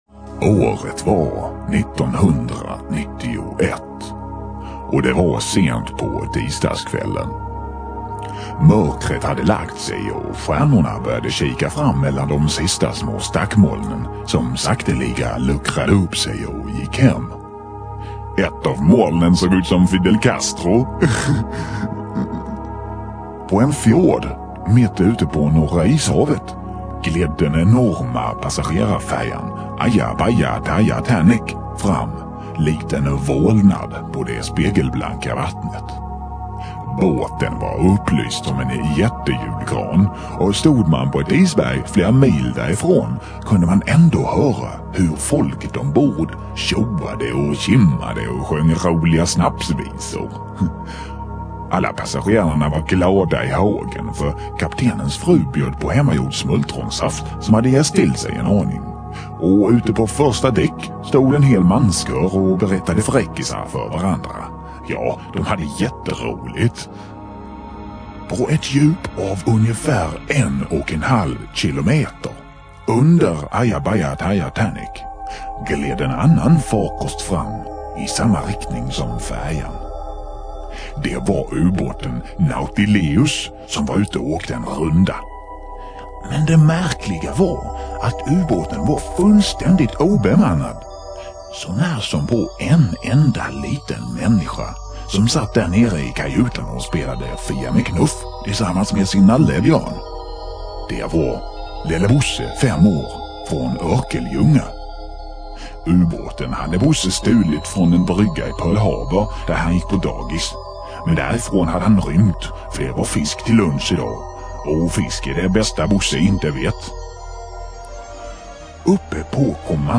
"Lelle Bosse" berättelser
Lo-fi filerna är samma som originalet men i mono och lite sämre ljudkvalisort - snabbare att ladda hem.